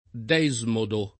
desmodo [ d $@ modo ]